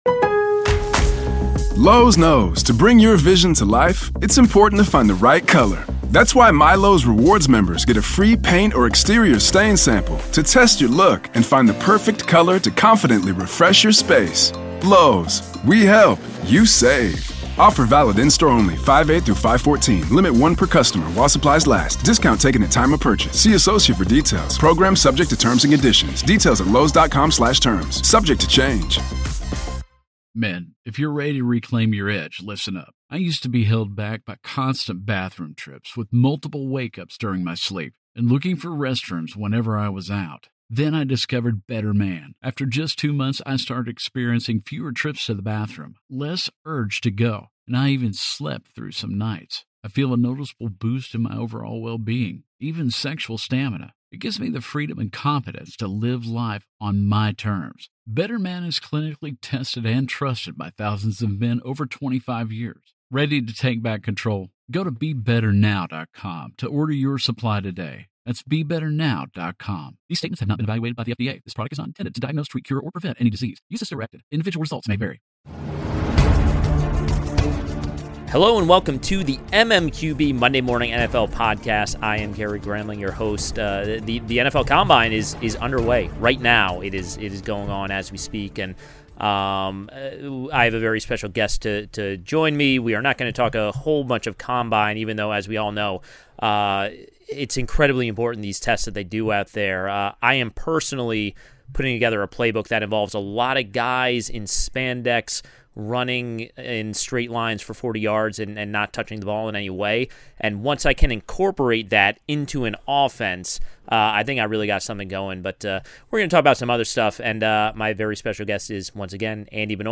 Always-special guest